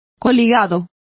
Complete with pronunciation of the translation of allied.